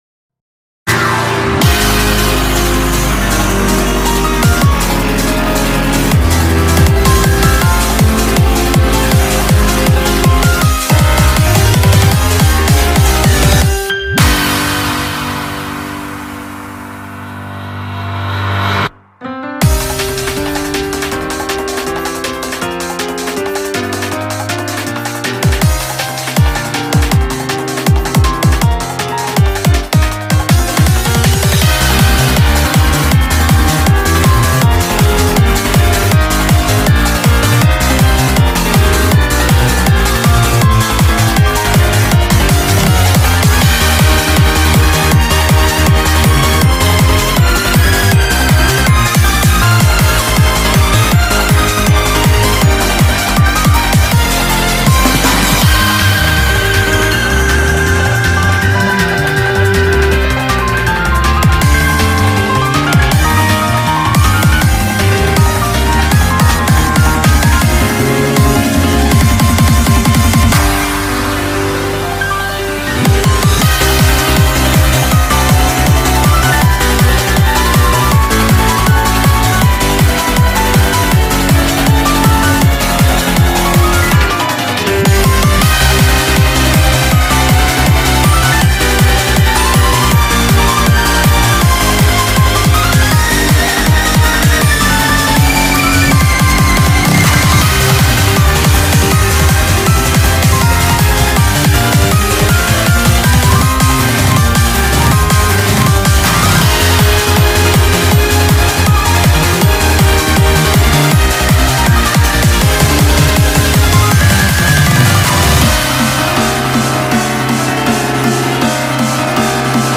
BPM160
Audio QualityPerfect (Low Quality)